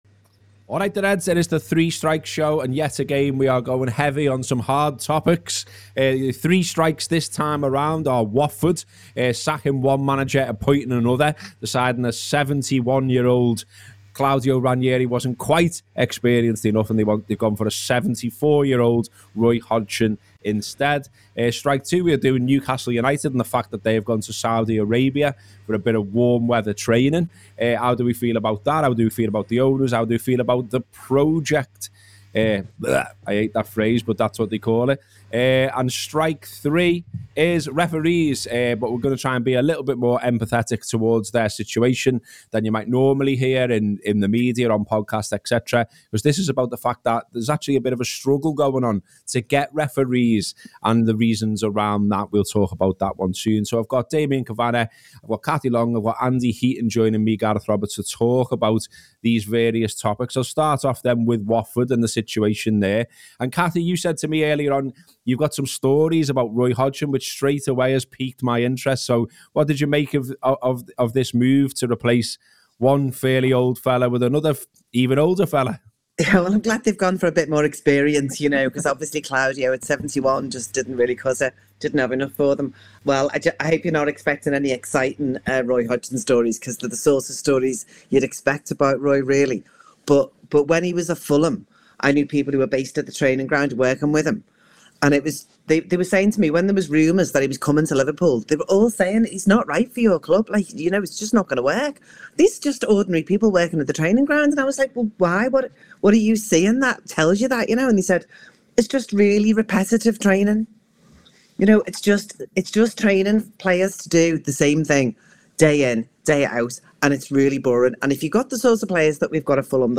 The three talking points the panel discuss are Watford’s manager merry-go-round, Newcastle United travelling to Saudi Arabia and referees.